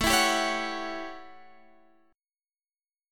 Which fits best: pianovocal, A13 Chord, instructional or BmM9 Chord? A13 Chord